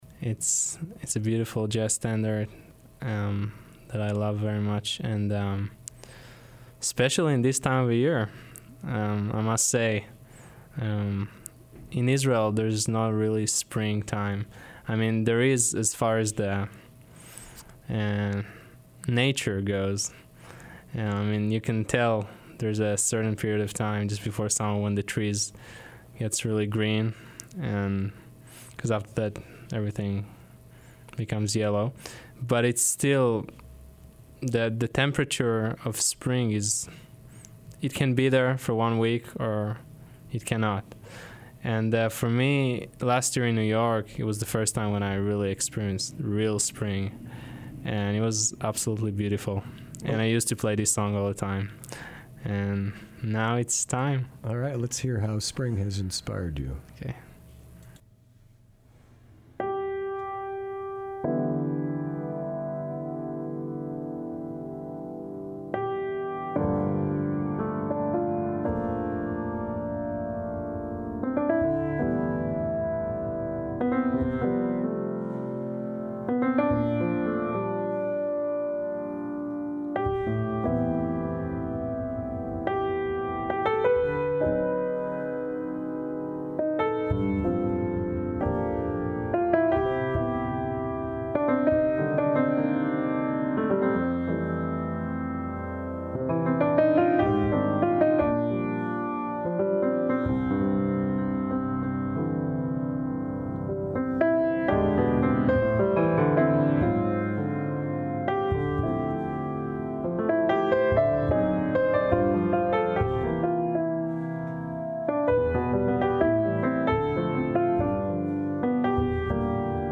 piano
Performance studio